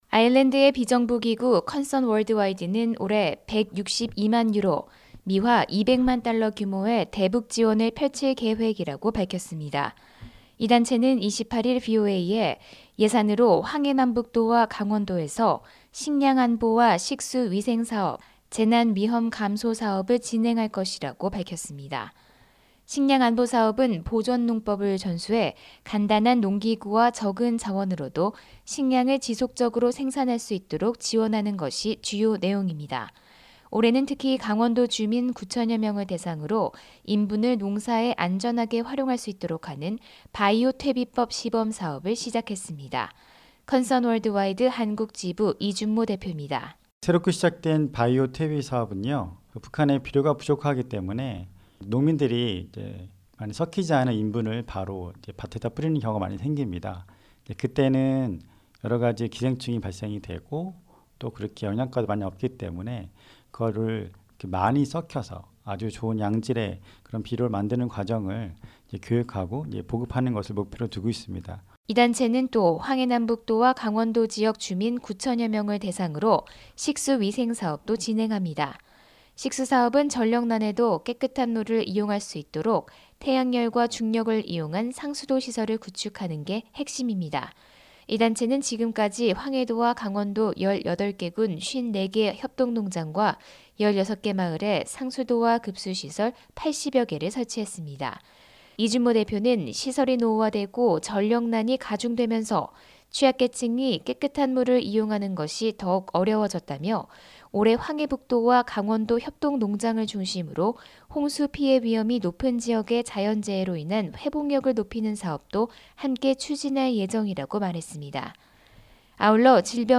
[특파원 리포트] 아일랜드 NGO, 올해 200만 달러 규모 대북 지원